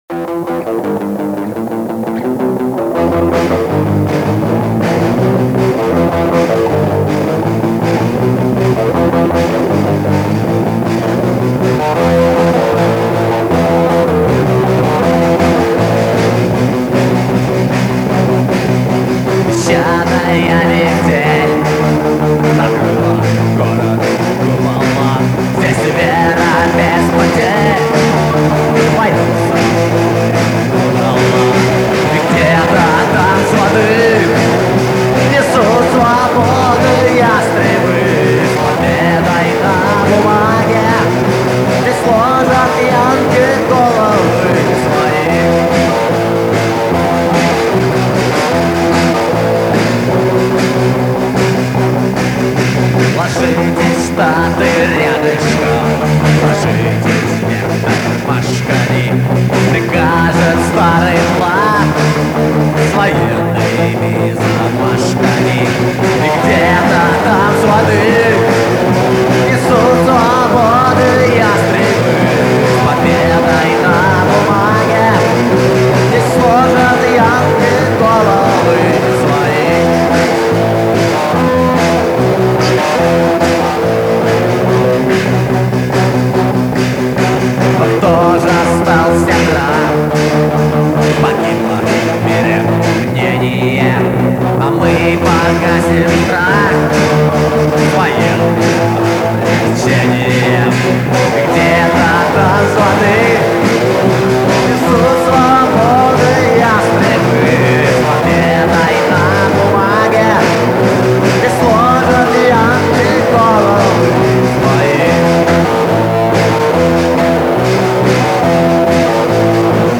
плохое!